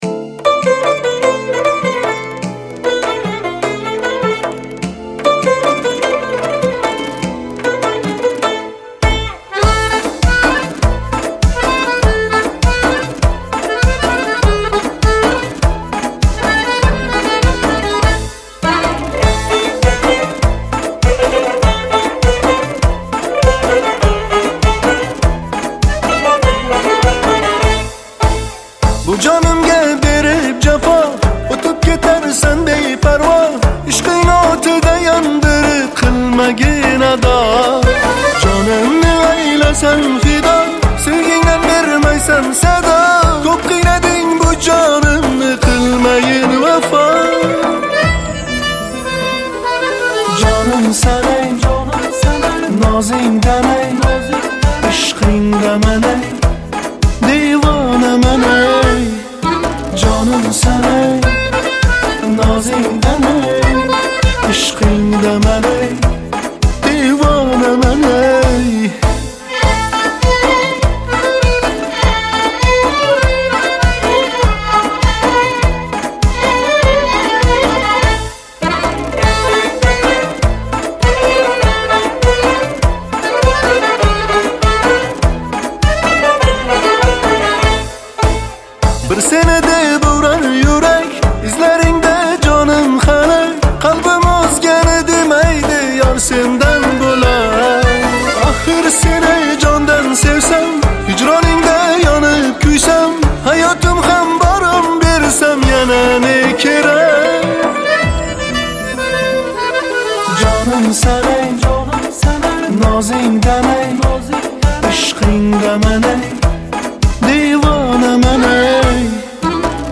Жанр: Узбекская музыка